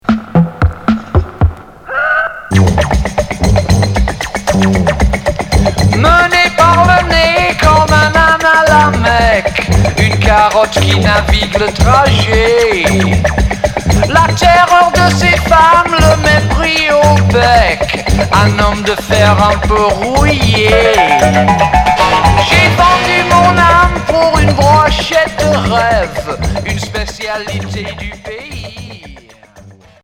New-wave expérimental dada